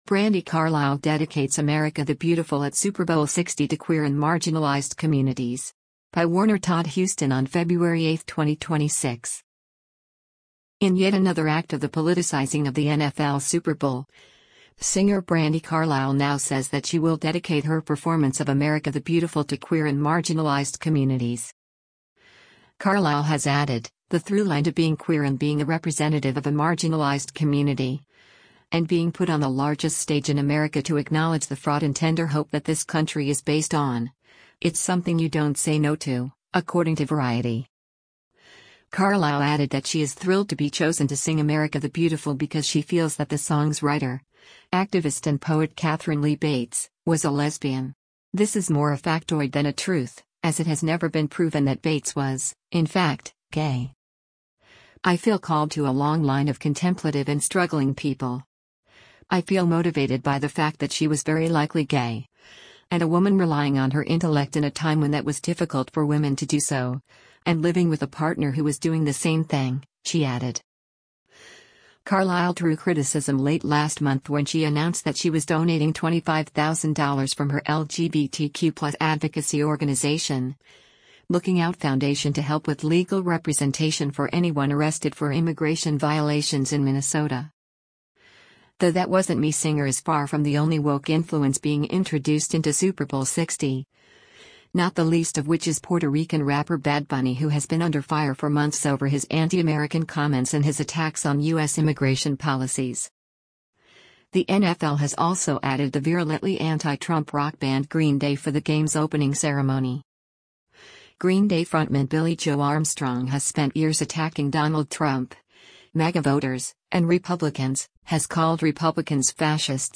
SANTA CLARA, CALIFORNIA - FEBRUARY 08: Brandi Carlile performs "America the Beautiful